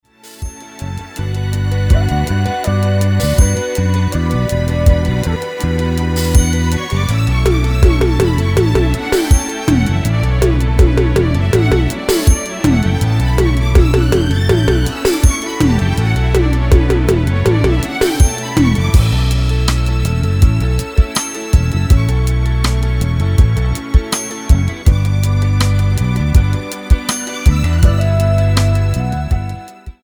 --> MP3 Demo abspielen...
Tonart:Bm ohne Chor